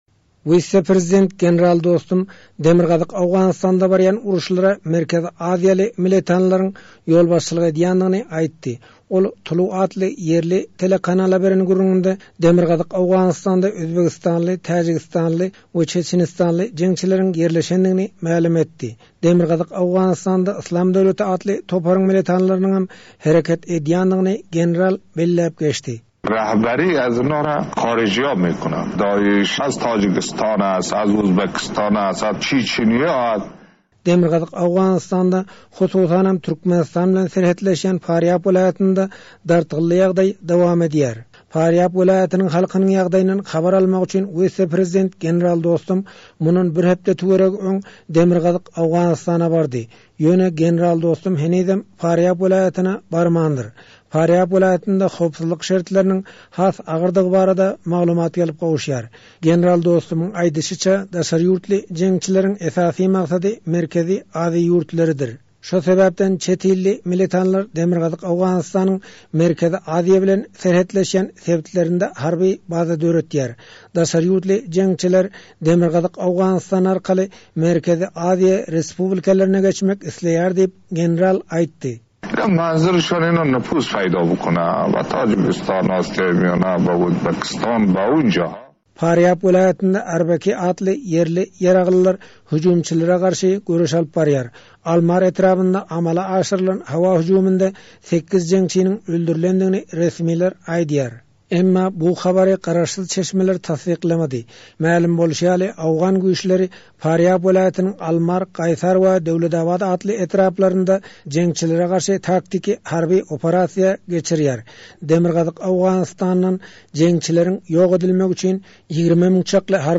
Demirgazyk Owganystanda wise-prezident General Abdul Reşit Dostum žurnalistleriň öňünde çykyş edip, Farýabda durnuksyzlyga sebäp bolýan esasy güýjüň daşary ýurtlulardygyny aýtdy. Häzir Farýab bilen goňşy Jowzjan welaýatynda bolýan Dostum Farýaby söweşijilerden arassalamak wezipesini öz öňünde goýýan owgan harbylaryna ýolbaşçylyk edýär.